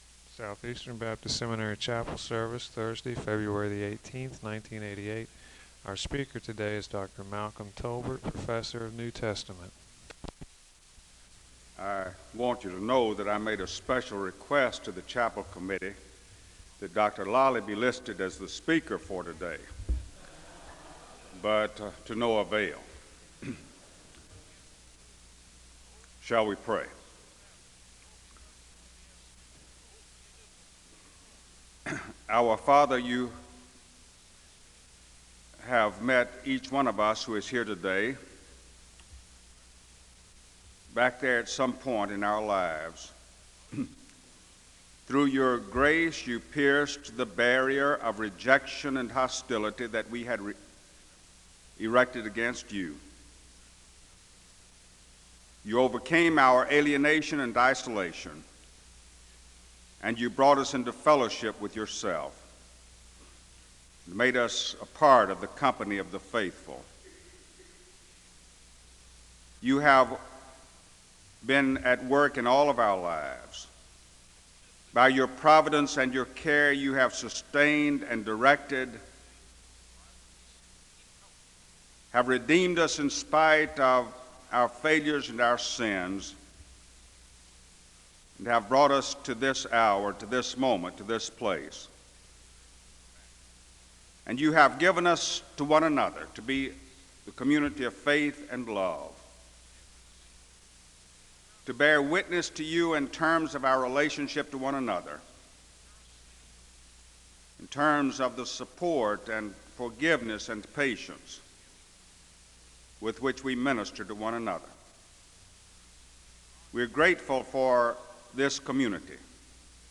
The service begins with a word of prayer (0:00-2:45).
The service concludes with a moment of prayer (21:06-21:27).
SEBTS Chapel and Special Event Recordings SEBTS Chapel and Special Event Recordings